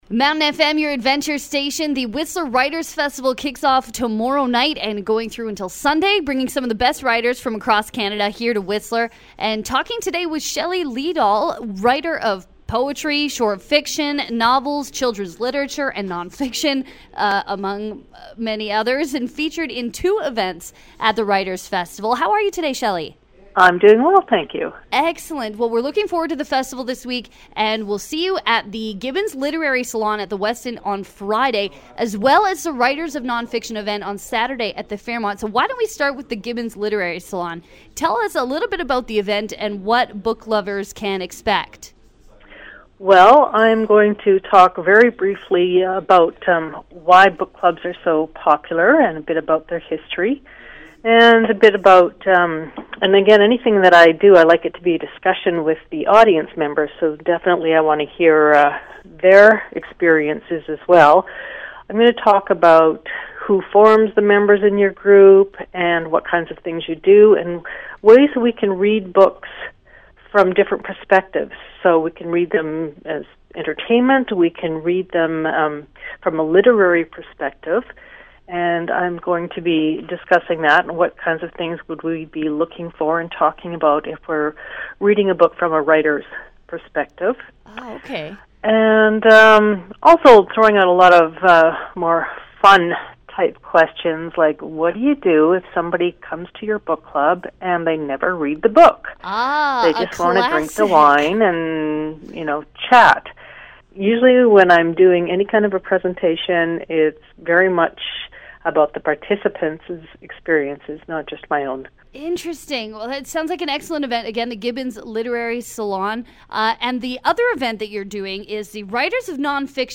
Whistler, BC